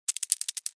Звук паучьих лап